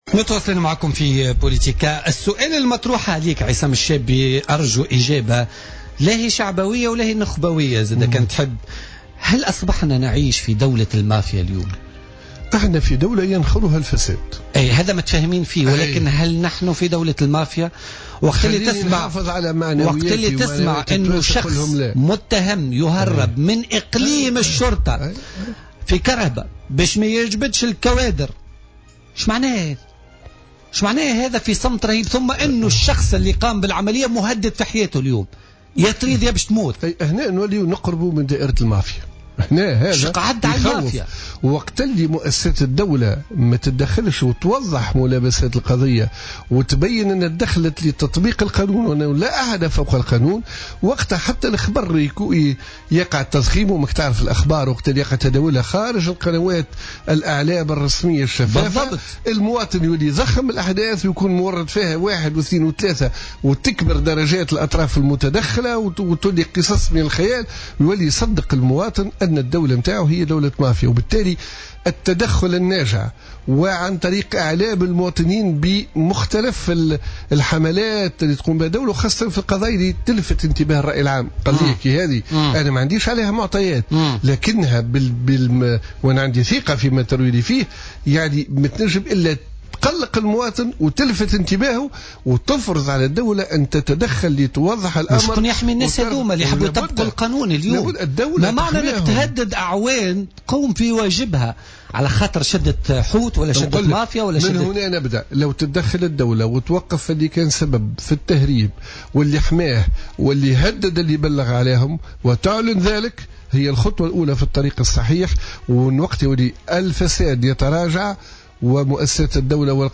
وأقر ضيف "بوليتيكا" بأننا نحن في دولة ينخرها الفساد ، داعيا السلطات لتوضيح ملابسات هذه القضية بعد فرار المتهم من مركز الإيقاف بمصالح الديوانة في سوسة أمس.